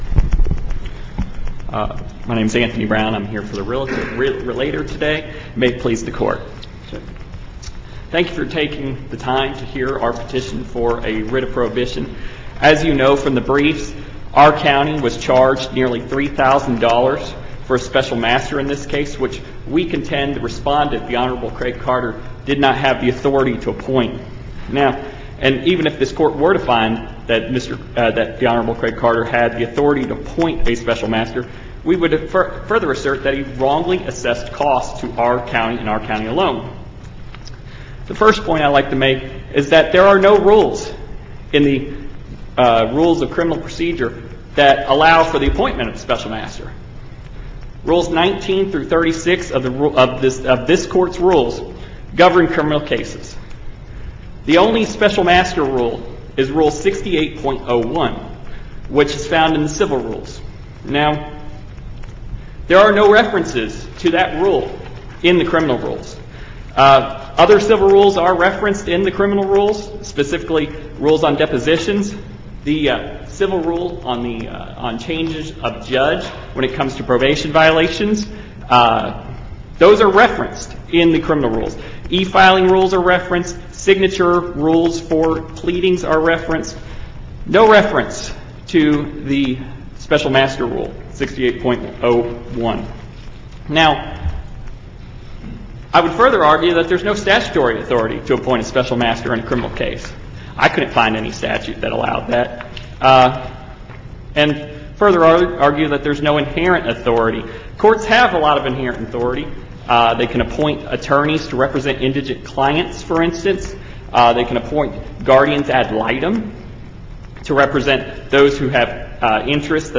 MP3 audio file of oral arguments in SC95538